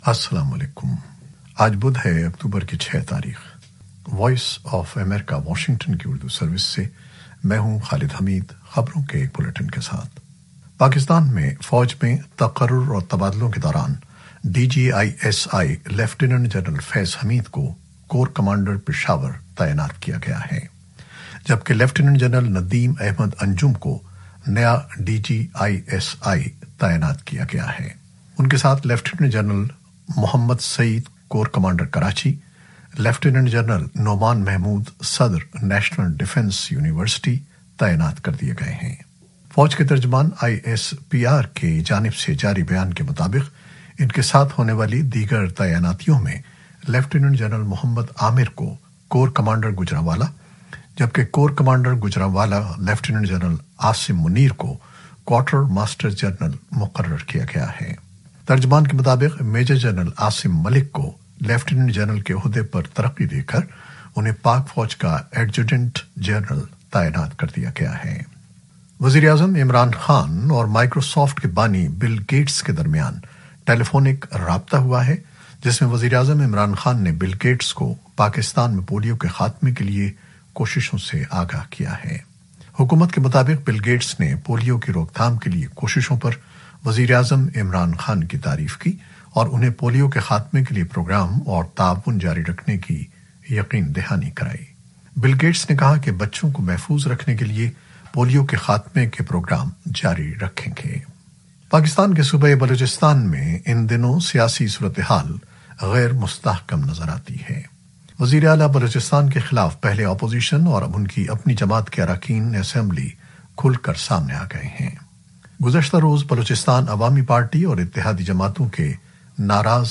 نیوز بلیٹن 2021-06-10